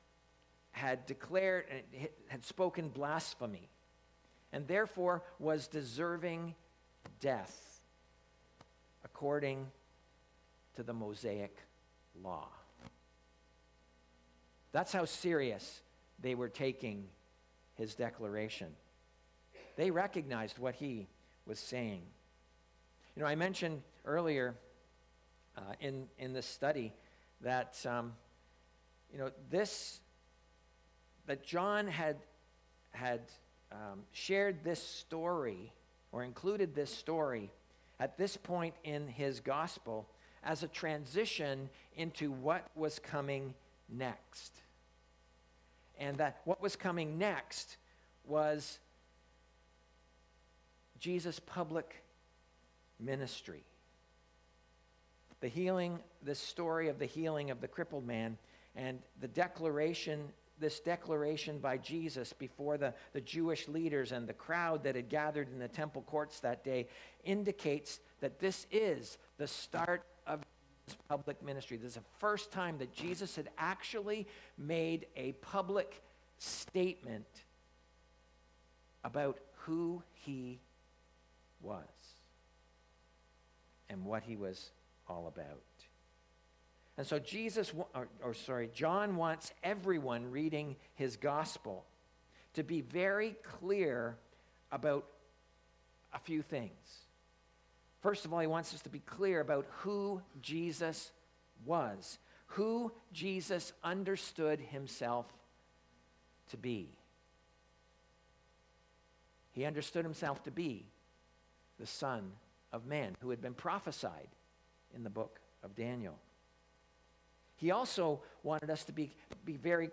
Sermons | Edmison Heights Baptist
Due to a technical problem the first 5-7 minutes of today's message was lost on our audio recording.